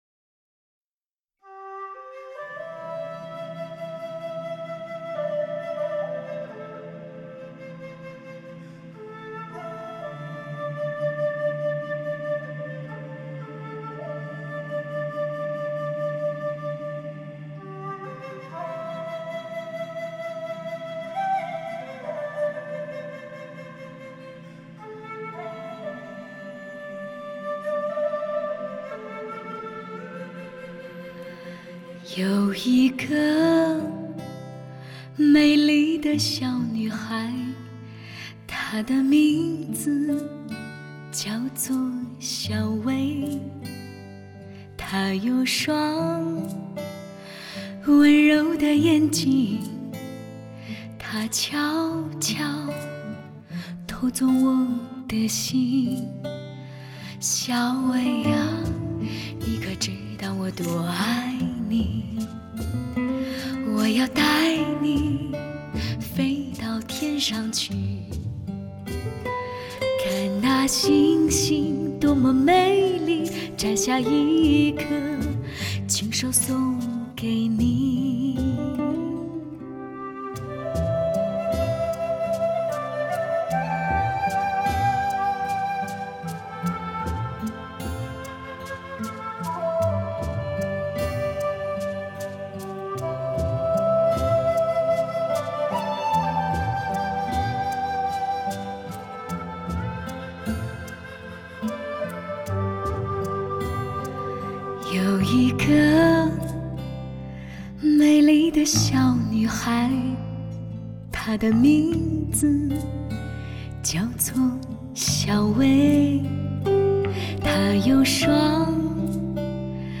一首首耳熟能详的经典流行歌曲，
丝绸质感的女中低音，亚洲第一萨克斯倾情演绎；
西洋爵士乐、中东打击乐，中国民乐融汇一体。